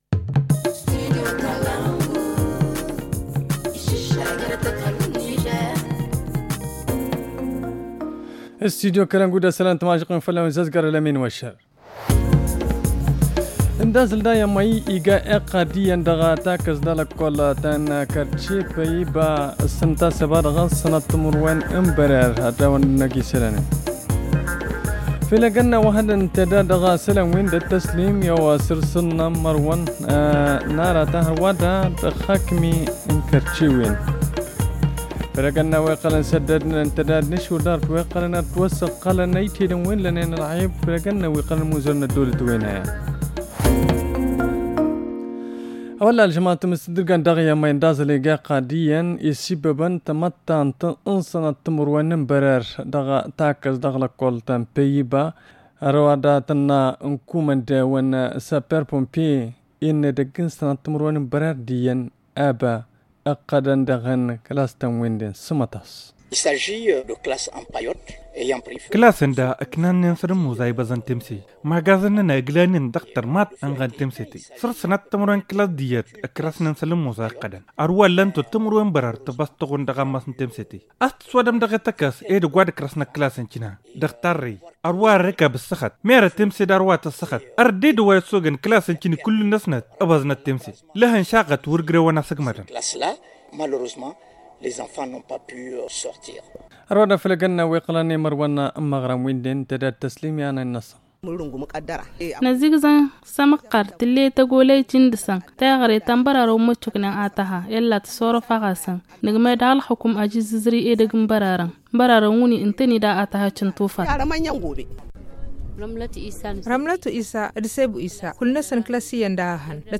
Le journal du 14 avril 2021 - Studio Kalangou - Au rythme du Niger